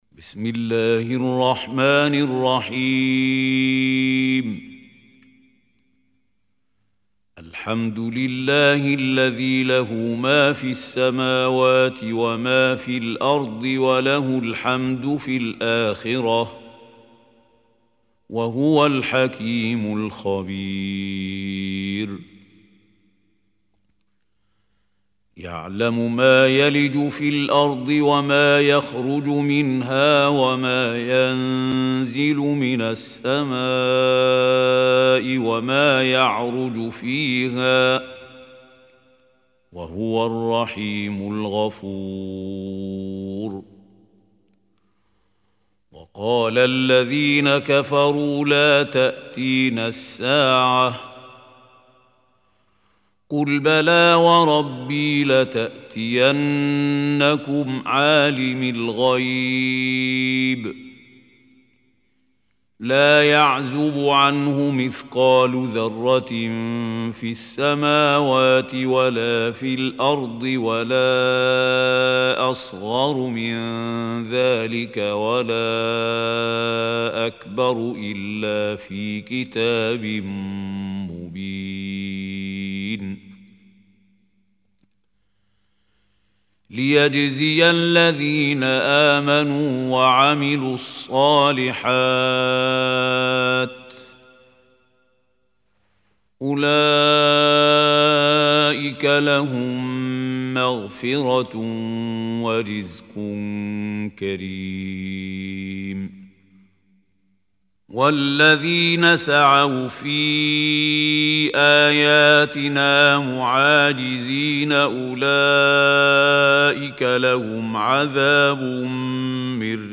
Surah Saba Recitation by Mahmoud Khalil Hussary
Surah Saba is 34 surah of Holy Quran. Listen or play online mp3 tilawat / recitation of Surah Saba in the beautiful voice of Mahmoud Khalil Al Hussary.